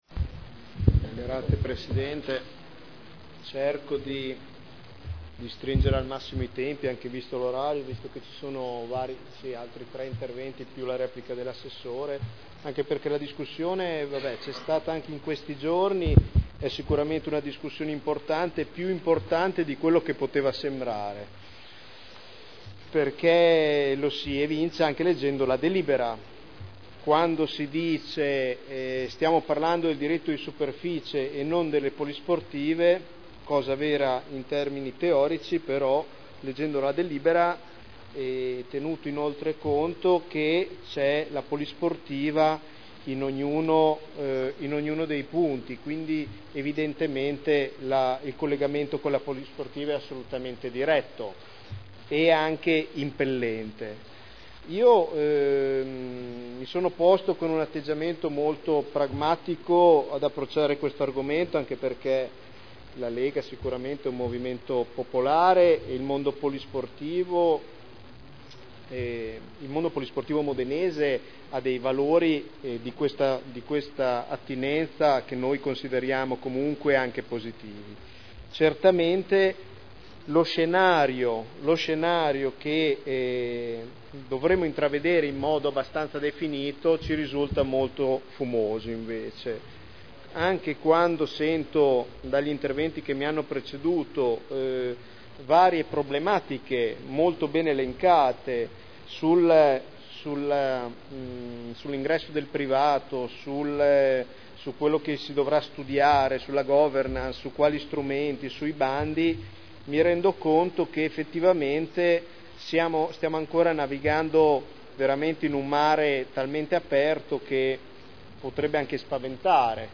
Nicola Rossi — Sito Audio Consiglio Comunale
Seduta del 13/12/2010 Deliberazione: Approvazione degli indirizzi per la concessione in diritto di superficie di aree comunali Dibattito